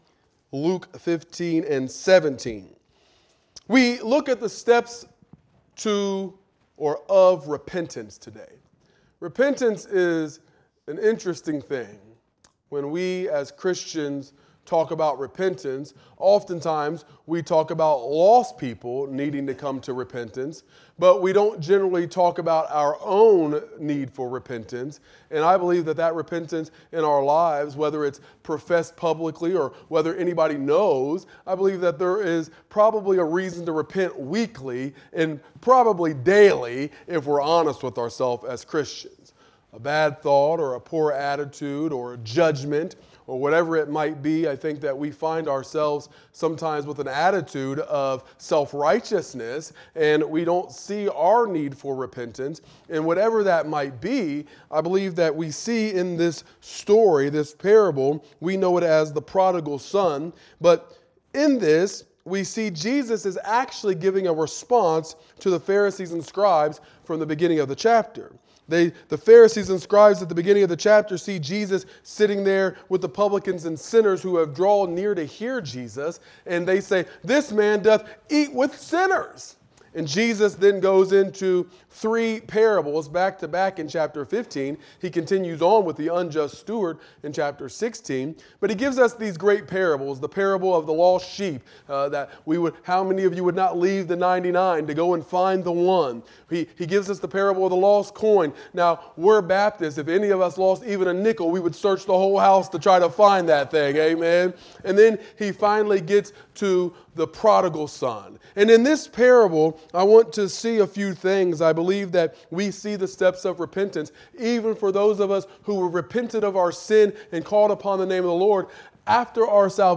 Wednesday Noon Bible Study